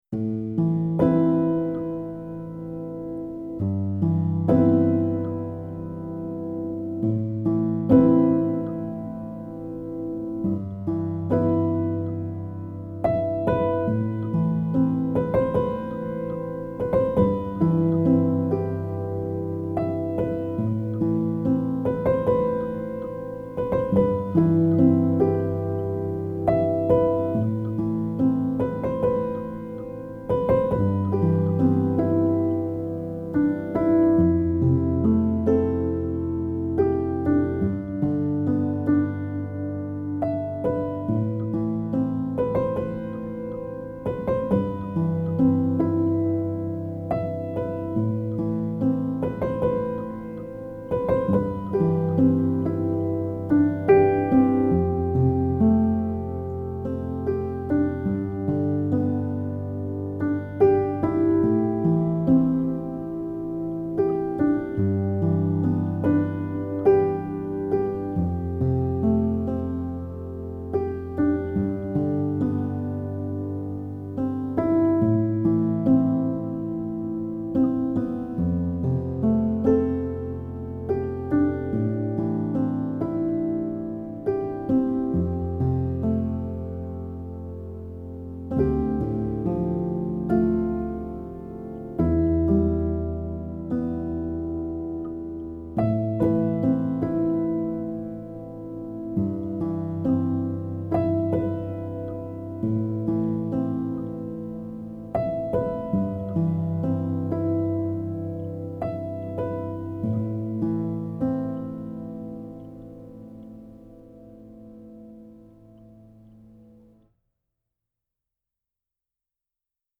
موسیقی بی کلام پیانو